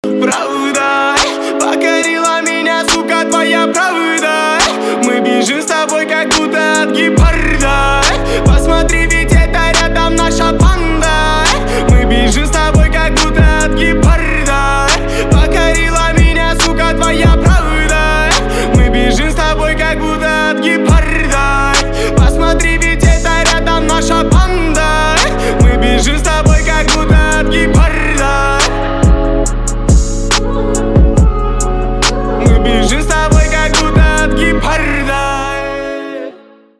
• Качество: 320, Stereo
громкие
Хип-хоп
русский рэп
Trap
Bass